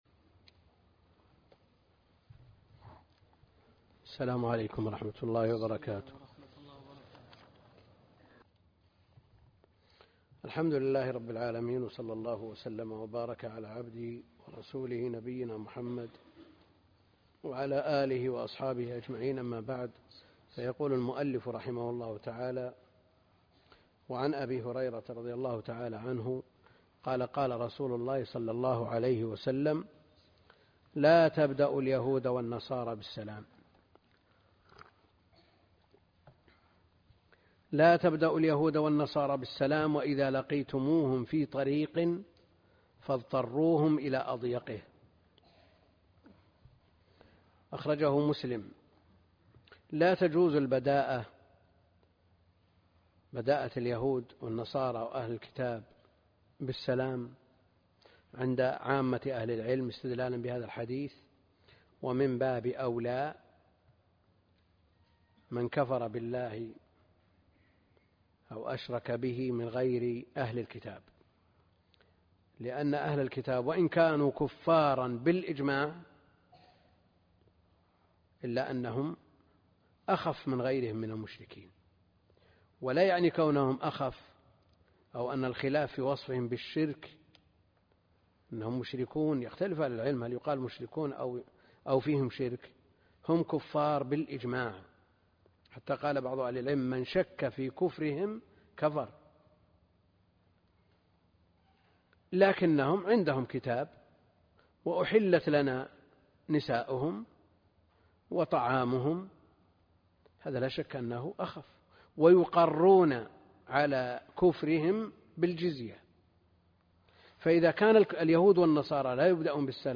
الدرس (2) كتاب الجامع من بلوغ المرام - الدكتور عبد الكريم الخضير